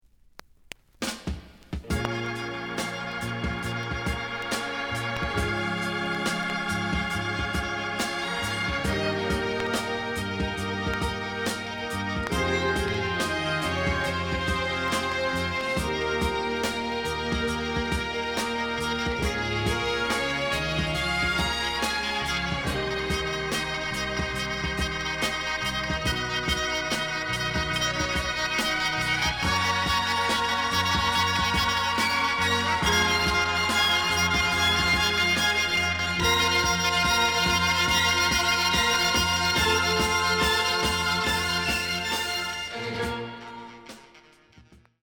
The audio sample is recorded from the actual item.
●Genre: Funk, 60's Funk
Some click noise on both sides due to scratches.